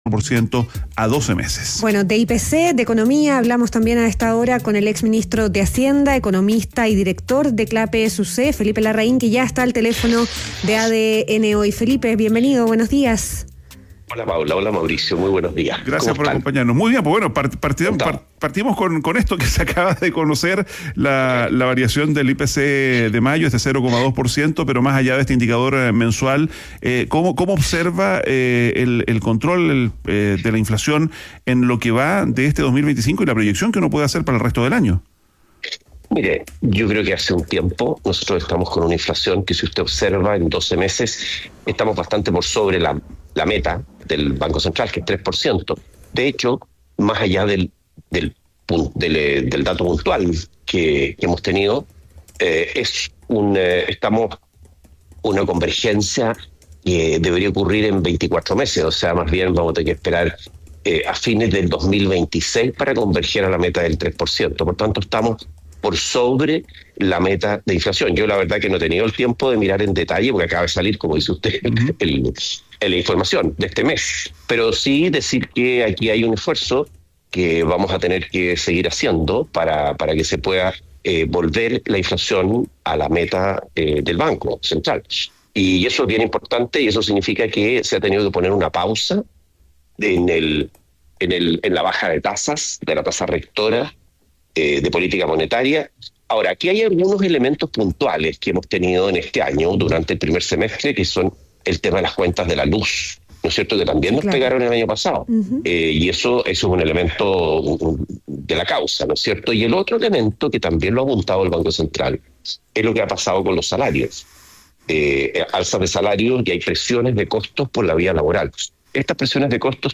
En conversación con ADN Hoy, el exministro de Hacienda e integrante del equipo económico de la candidata presidencial, Evelyn Matthei, acusó omisiones del Mandatario y apuntó a la falta de medidas concretas para enfrentar el estancamiento económico.